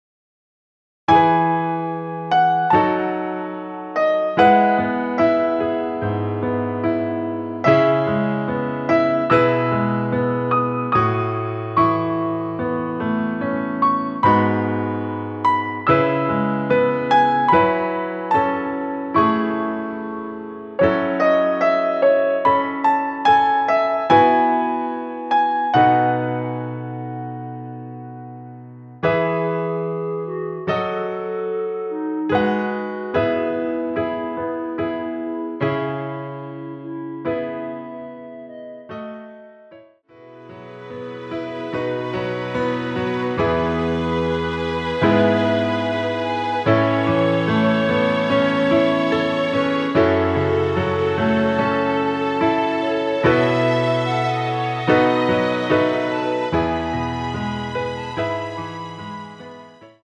피아노와 스트링만으로 편곡한 MR 입니다.
원키에서(+4)올린 어쿠스틱버전 멜로디 포함된 MR 입니다.(미리듣기 참조)
앞부분30초, 뒷부분30초씩 편집해서 올려 드리고 있습니다.
(멜로디 MR)은 가이드 멜로디가 포함된 MR 입니다.